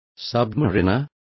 Complete with pronunciation of the translation of submariners.